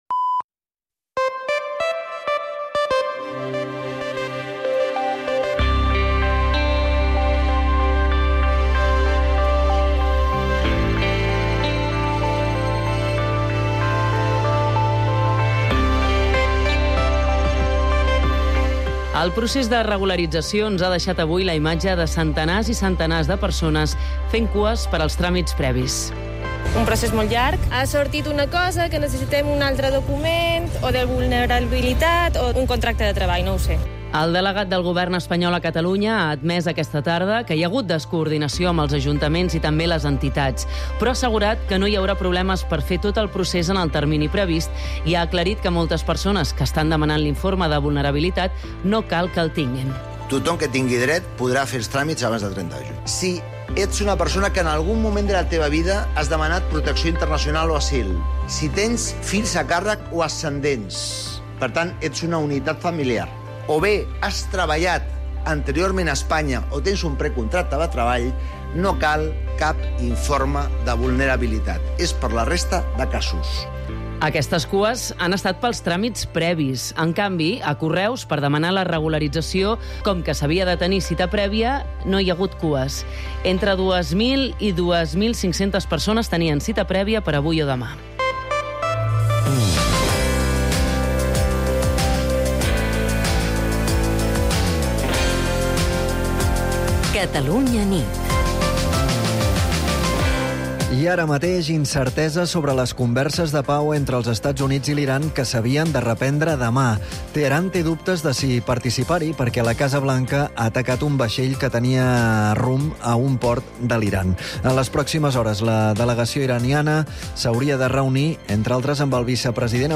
Informatius